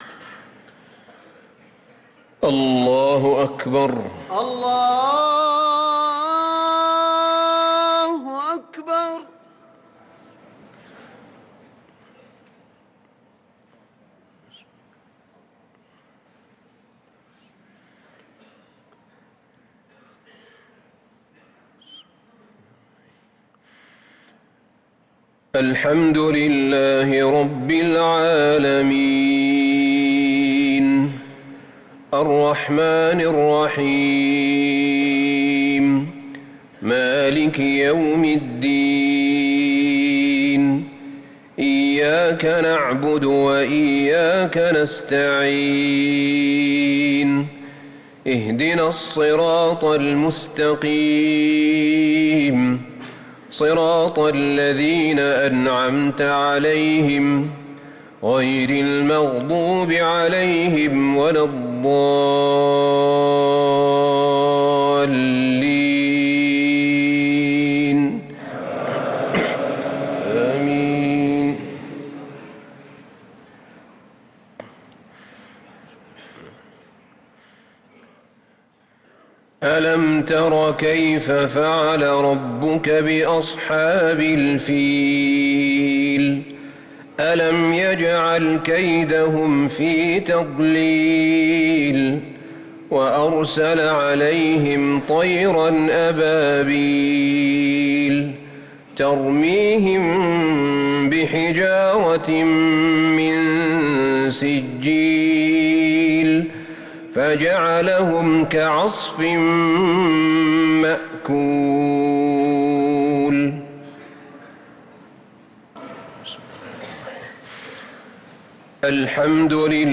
صلاة المغرب للقارئ أحمد بن طالب حميد 15 جمادي الأول 1445 هـ